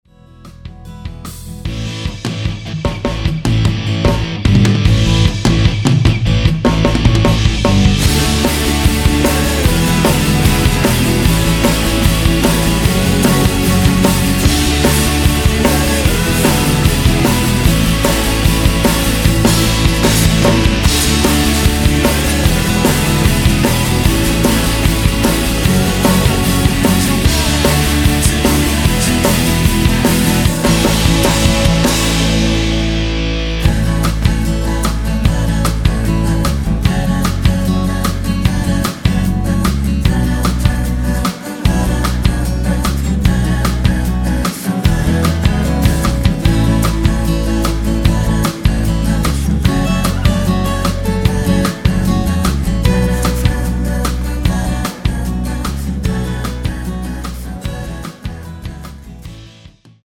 원키에서 코러스 포함된 MR입니다.(미리듣기 확인)
앞부분30초, 뒷부분30초씩 편집해서 올려 드리고 있습니다.
중간에 음이 끈어지고 다시 나오는 이유는